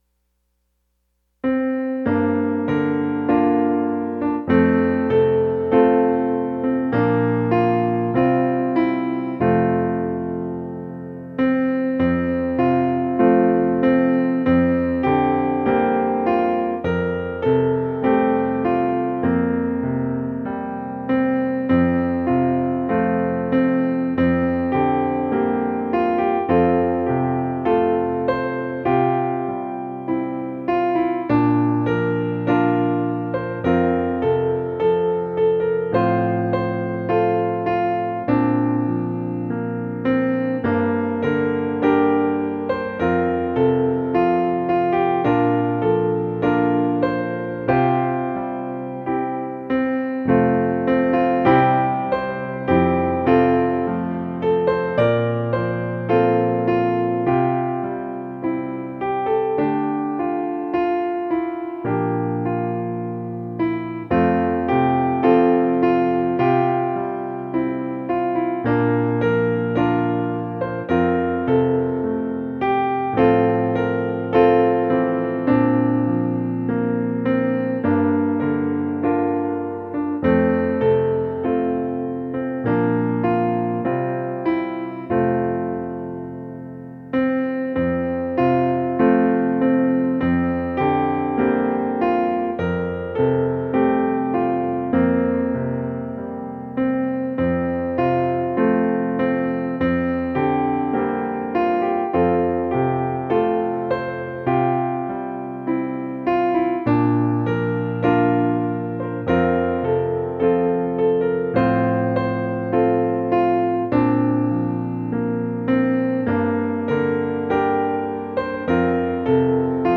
Choir Unison, Primary Children/Primary Solo
Violin Optional Obbligato/Violin Accompaniment